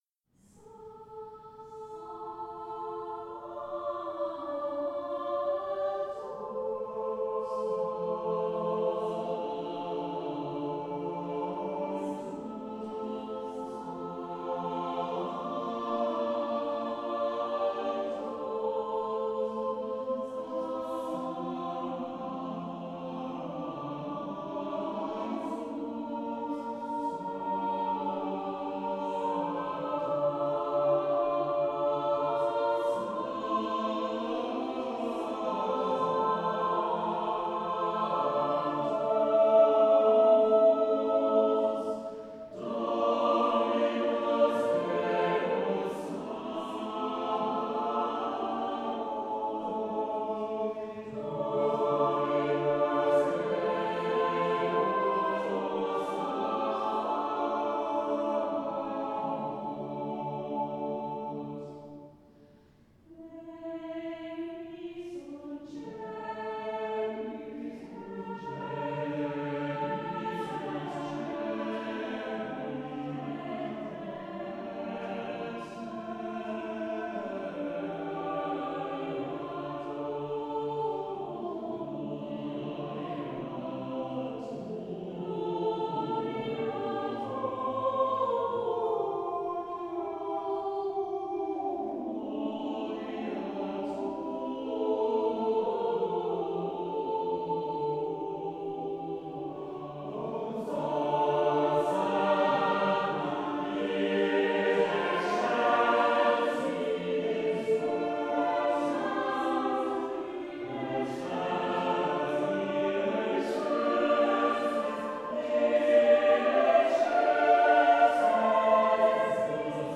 English choral music as it might have been sung
in the Priory Church through the centuries